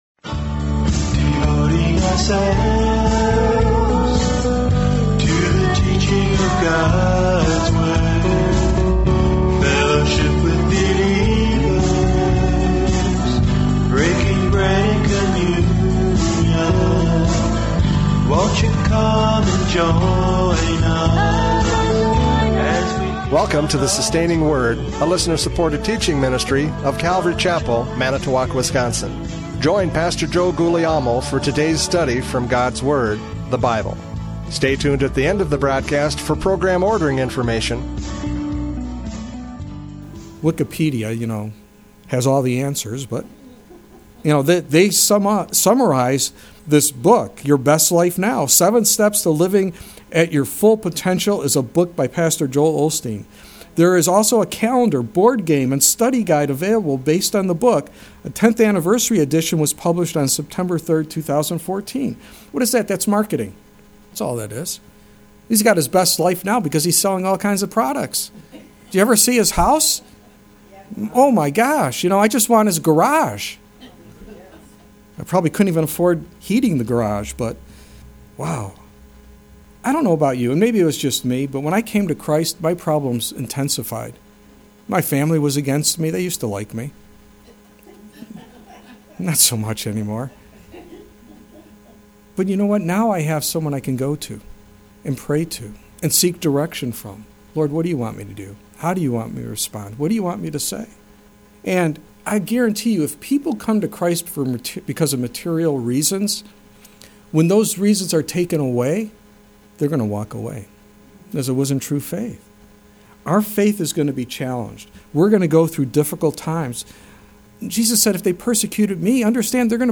John 6:60-71 Service Type: Radio Programs « John 6:60-71 True and False Disciples!